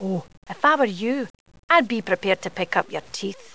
Sound Bites
Here are a few .wav files of Annah speaking. Her voice is done by the talented Sheena Easton, and she has an awesome accent.